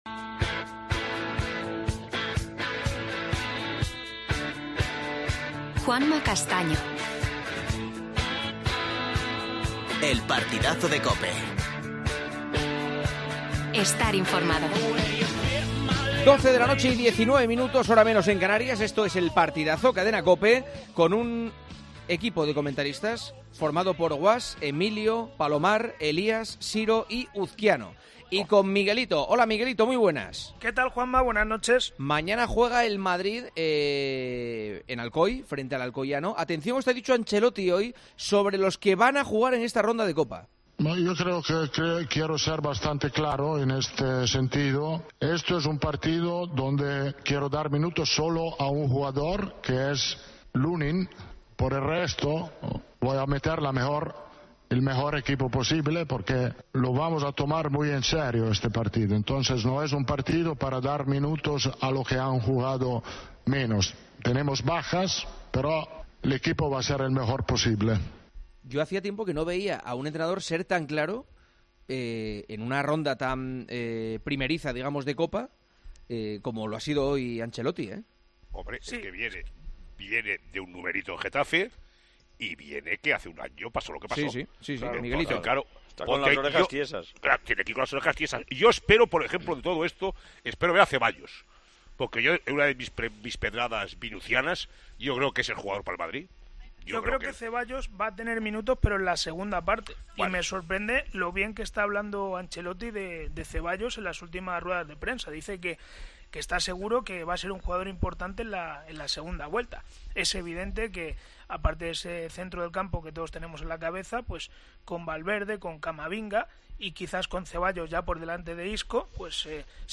AUDIO: Previa del Alcoyano - Real Madrid y rueda de prensa de Carlo Ancelotti. Entrevista al alcalde de Alcoy Toni Francés.